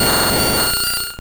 Cri de Rafflesia dans Pokémon Or et Argent.